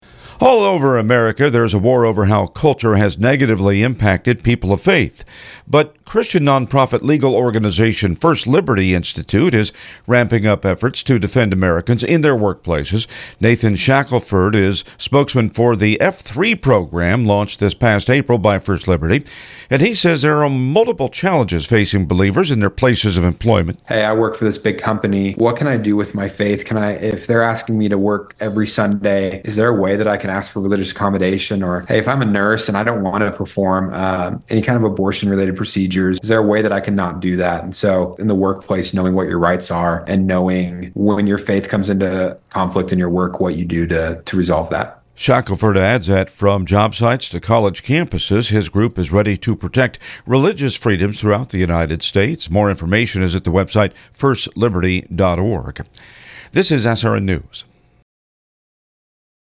F3 Initiative Featured on Salem Radio Network First Liberty launched new initiative called Future of Faith and Freedom (F3) . F3’s goal is to empower a new generation of leaders. It’s a growing movement of people in their 20s, 30s and 40s who aspire to be leaders in our nation’s coming culture shift.